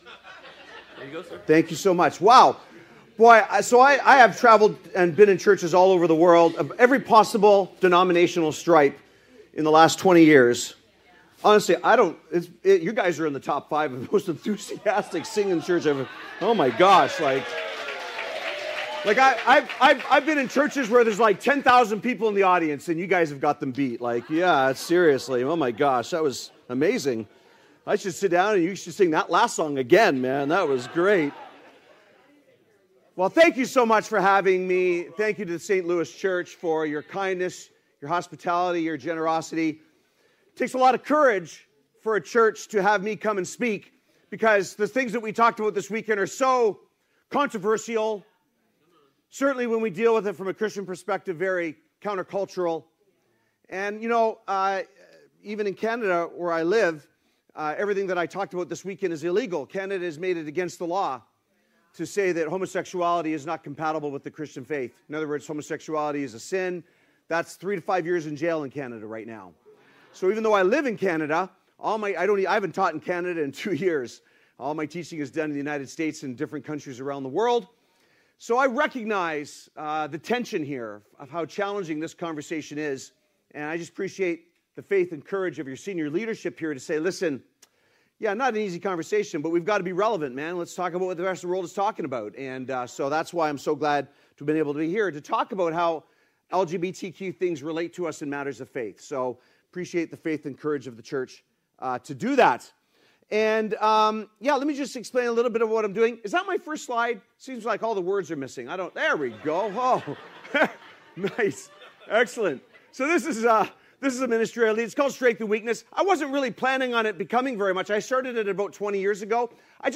Download Download Sunday Worship - Stand-Alone Sermons Current Sermon The Truth About Temptation Guest Speaker